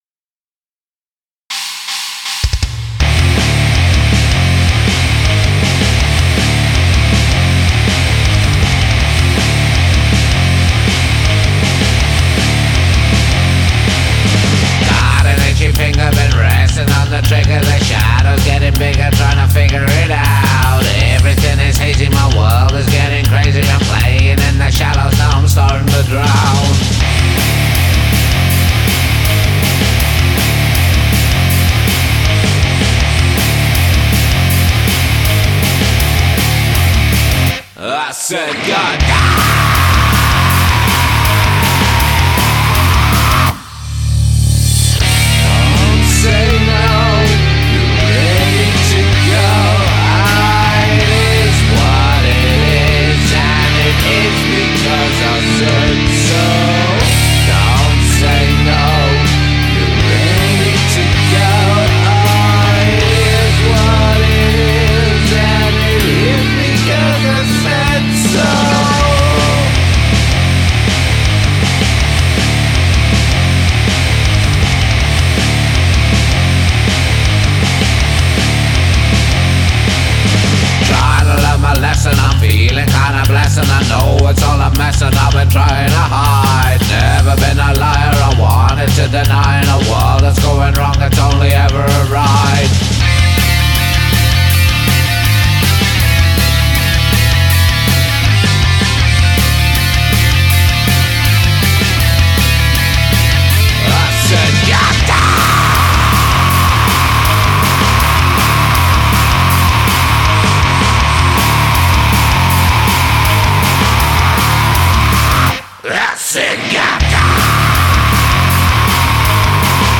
Metal Track - What Evil Lies in the Hearts of Men
Here's my latest effort mixing of our band using ableton live 9.